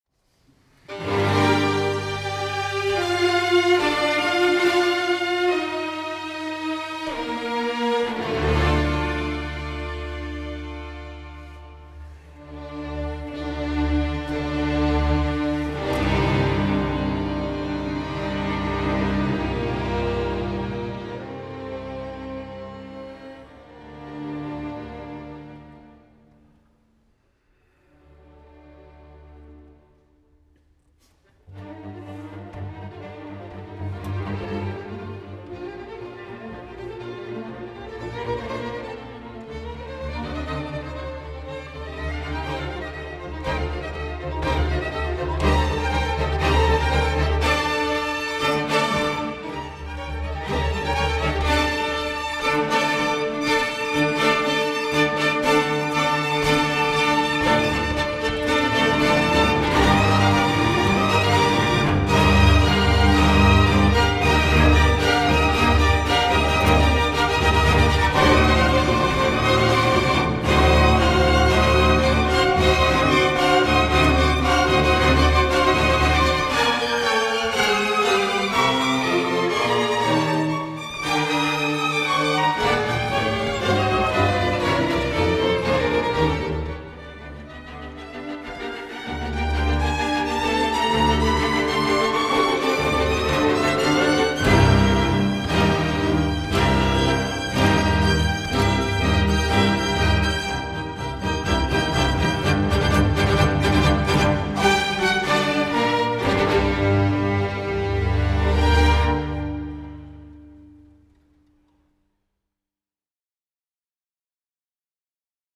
A pályázók feladata egy legalább 30, legfeljebb 120 másodperc hosszú, amatőr videófilm készítése, amely valamilyen módon Grieg: “1., g-moll vonósnégyes, op. 27 — vonószenekari előadásban” című művén alapul.